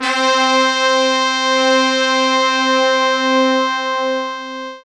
SI2 BRASS01L.wav